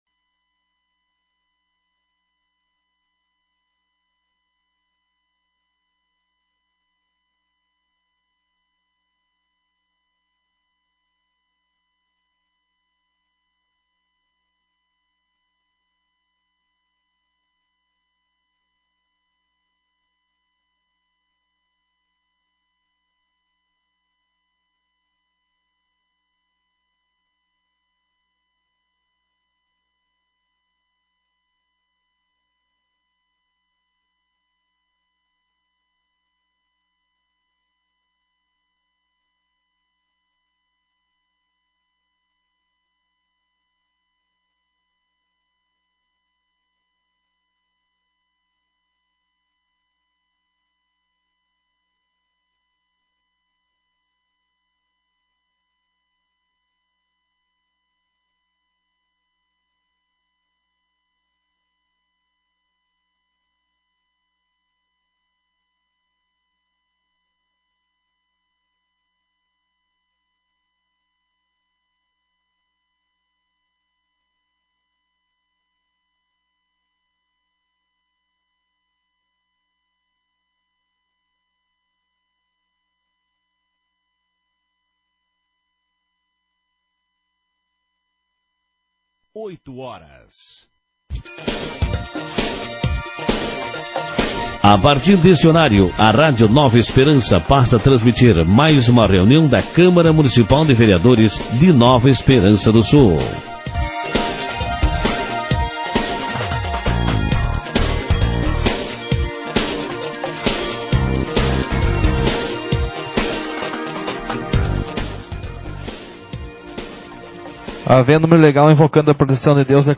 Sessão Ordinária 34/2023